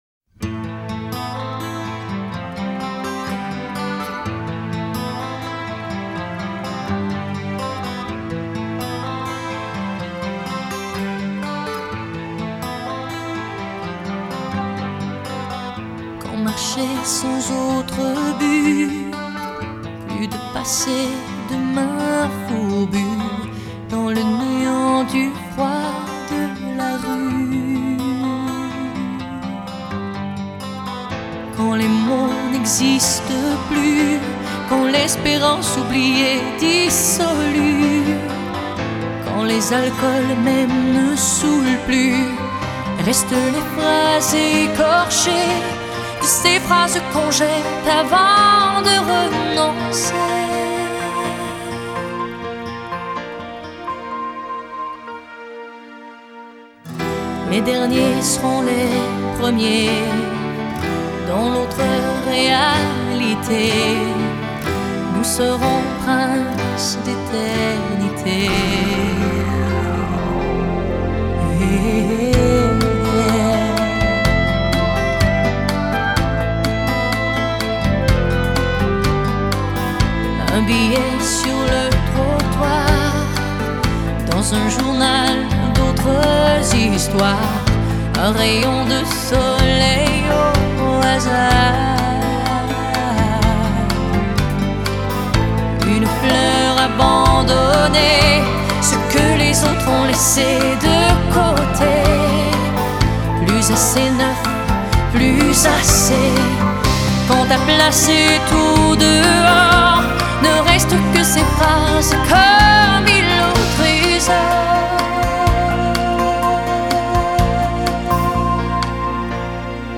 Live at Le Zénith, Paris, France - October 1995